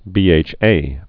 (bēāch-ā)